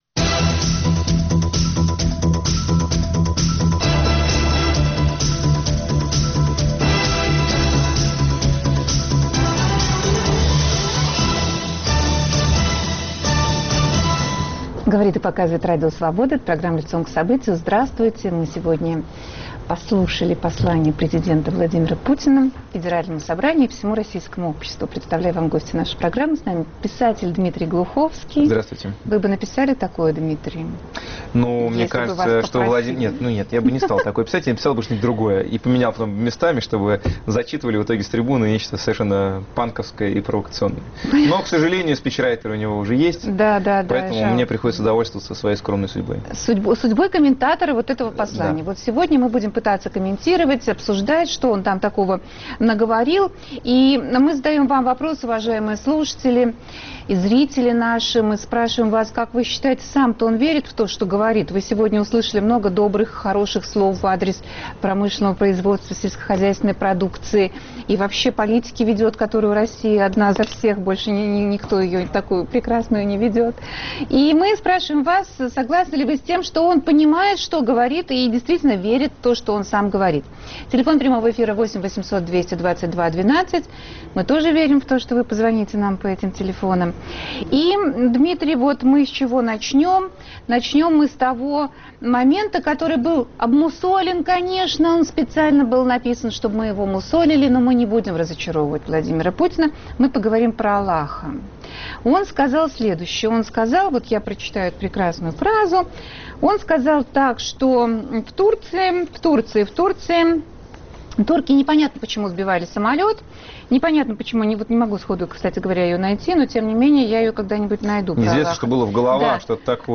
Насколько послание Владимира Путин адекватно состоянию дел в стране? В студии автор серии серии романов "Метро" о будущем Дмитрий Глуховский.